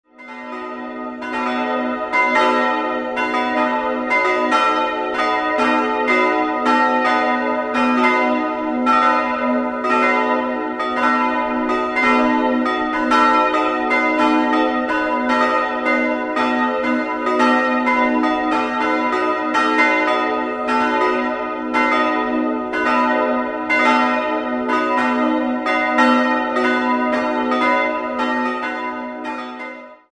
Jahrhundert umgestaltet wurde. 3-stimmiges Geläute: a'-h'-dis'' Die beiden größeren Glocken wurden 1922, die kleine 1923 in Bochum gegossen. Nach offiziellen Angaben müssten die Glocken eigentlich im verminderten Dreiklang a'-c''-es'' erklingen.